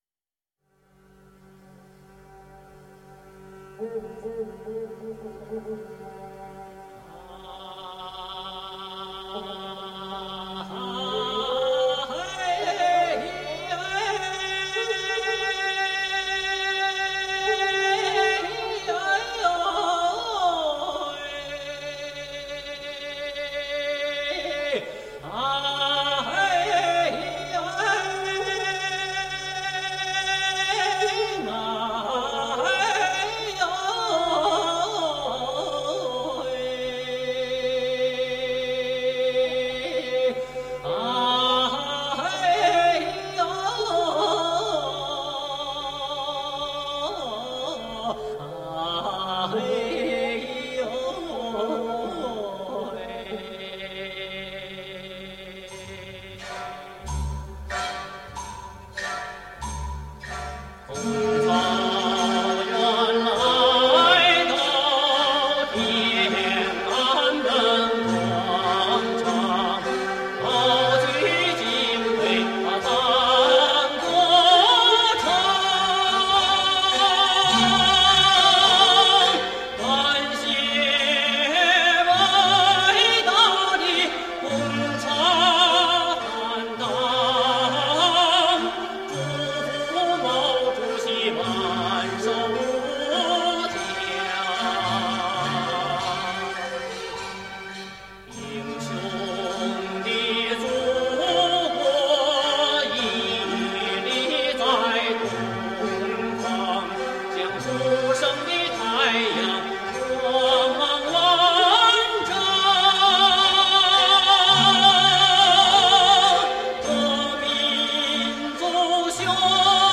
部分曲目现在听来音质可能不甚理想，但瑕不掩瑜。